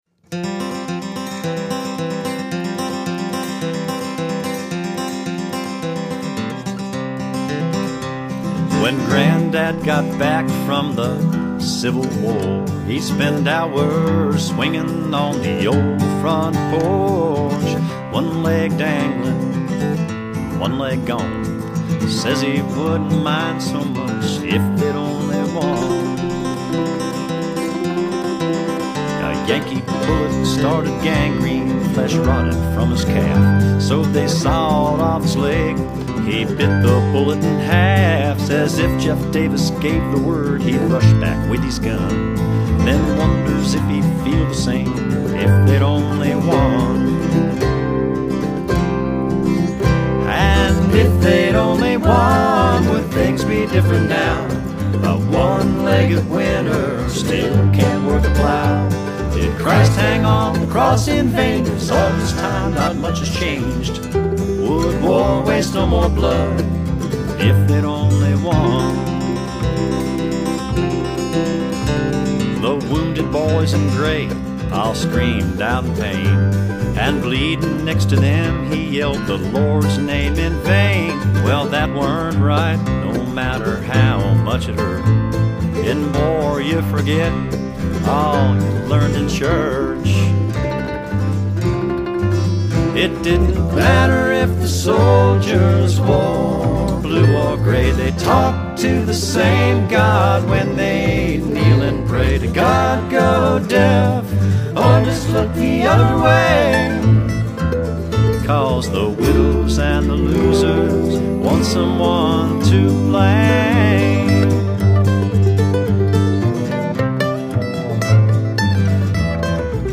Cowboy Poetry
guitar and vocals
bunkhouse bass and vocals
banjo, guitar and vocals.